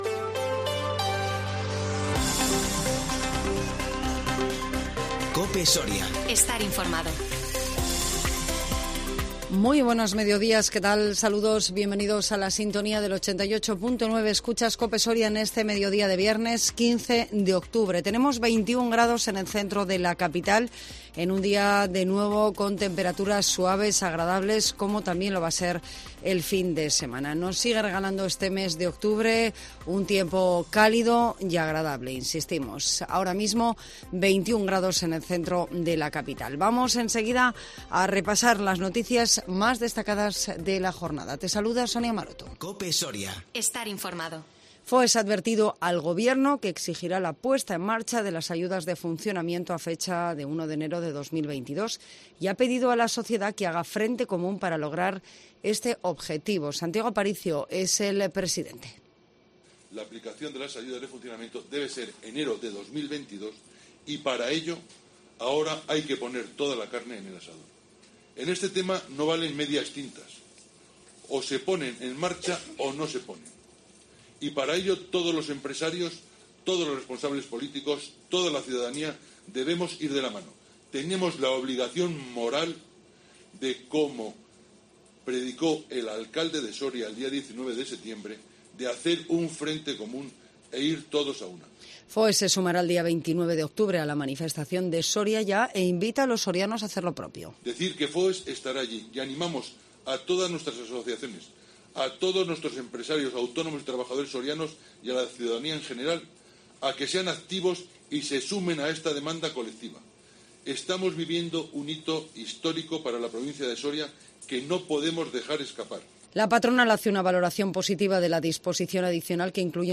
INFORMATIVO MEDIODÍA 15 OCTUBRE 2021